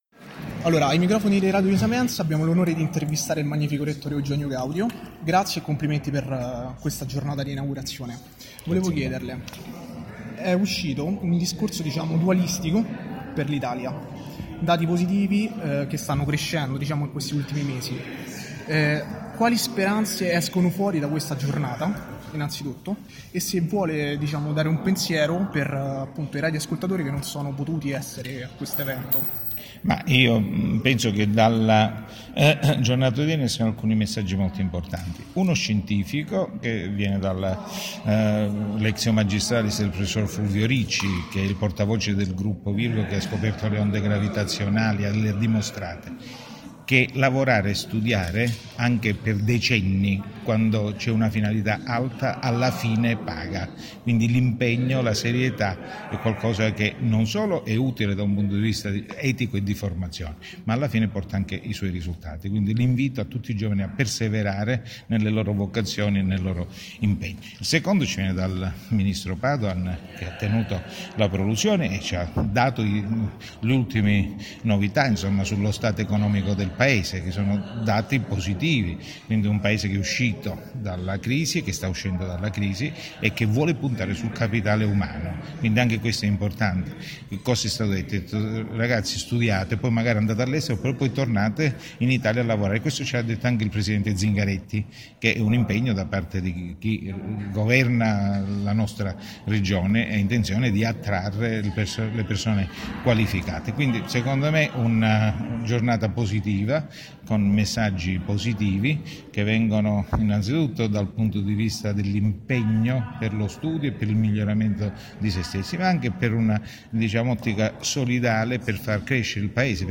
Giovedì 18 gennaio si è tenuta presso l’Aula Magna del Palazzo del Rettorato della Sapienza l’inaugurazione del nuovo anno accademico 2017-2018.
Intervista_Rettore-Eugenio-Gaudio.mp3